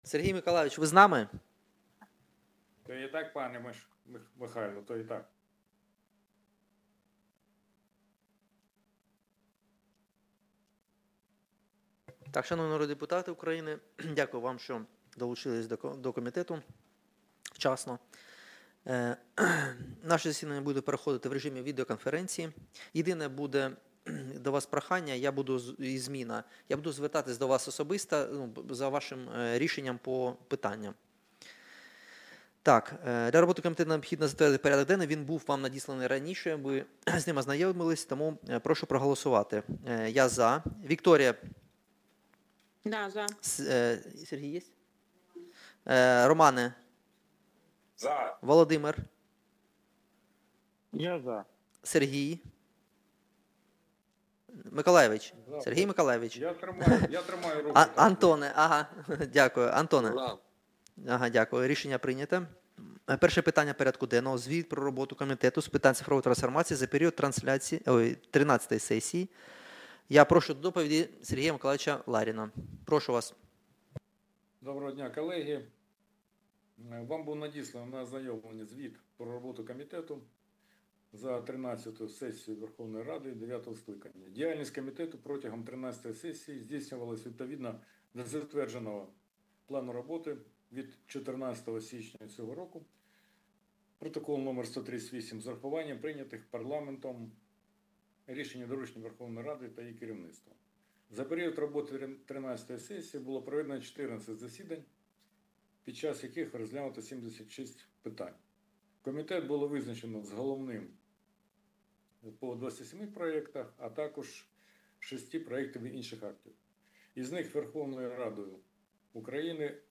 Аудіозапис засідання Комітету від 01.08.2025